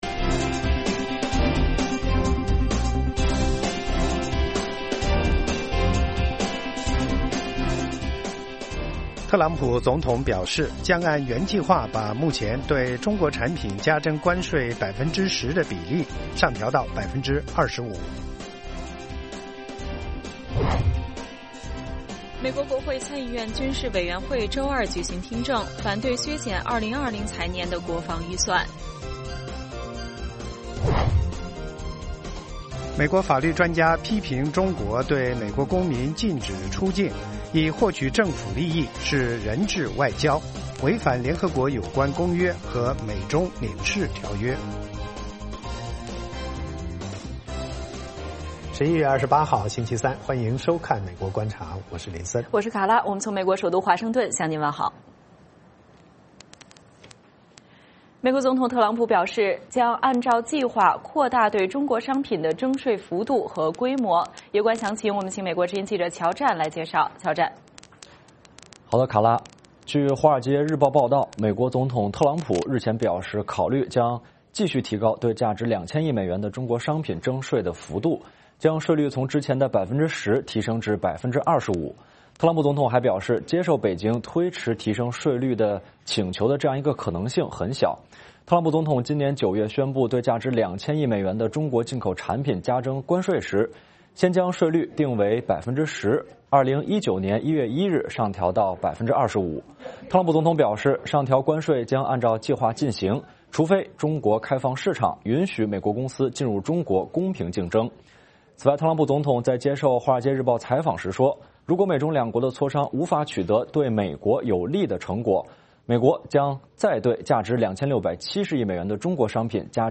北京时间早上6-7点广播节目，电视、广播同步播出VOA卫视美国观察。 “VOA卫视 美国观察”掌握美国最重要的消息，深入解读美国选举，政治，经济，外交，人文，美中关系等全方位话题。节目邀请重量级嘉宾参与讨论。